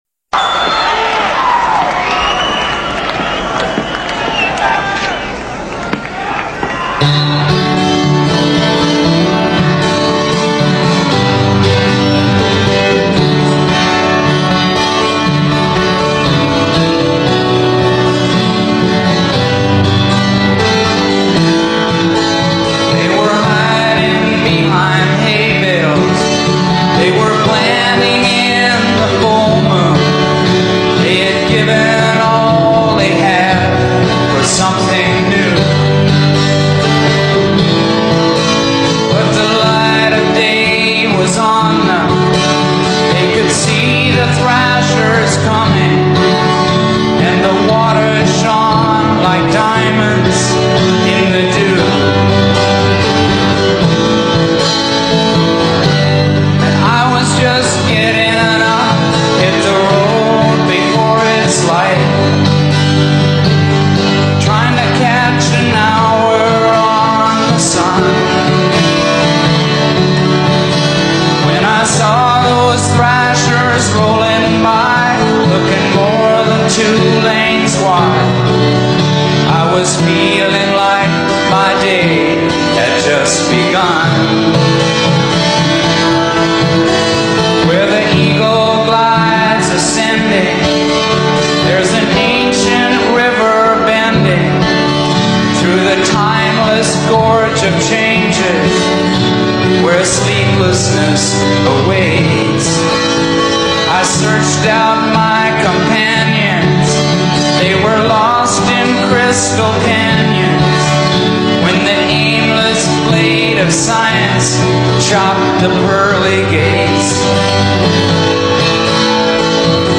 Maroc rock